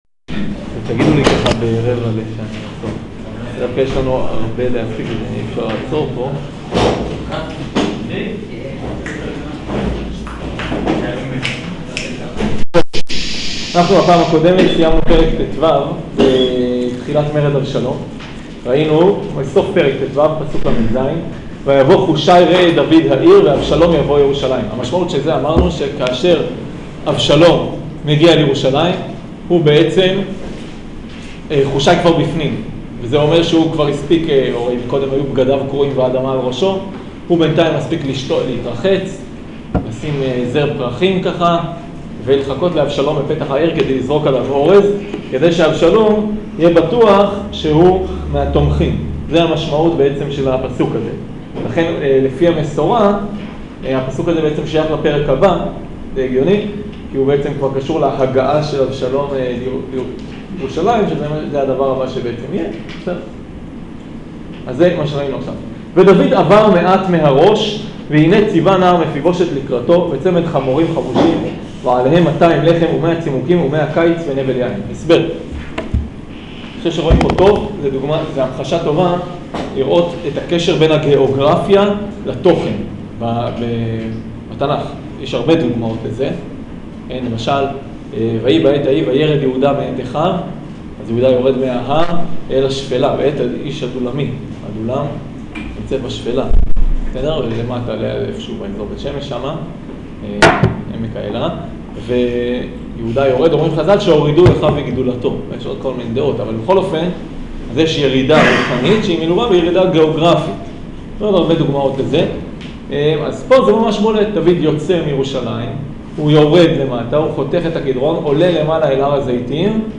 שיעור המשך המרד